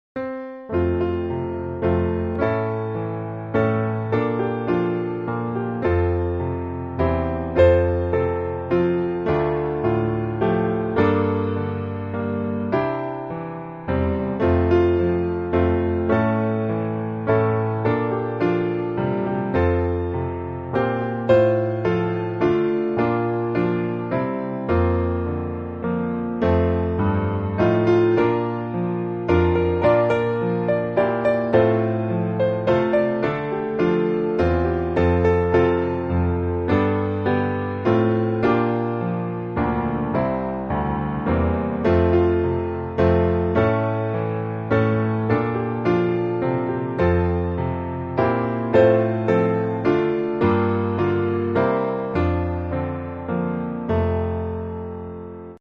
Details Loop: Song Forever Category: 追求與長進 Key: F Major Time: 3/4 Meter: Peculiar Meter.